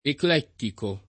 vai all'elenco alfabetico delle voci ingrandisci il carattere 100% rimpicciolisci il carattere stampa invia tramite posta elettronica codividi su Facebook eclettico [ ekl $ ttiko ] (raro ecclettico ) agg.; pl. m. ‑ci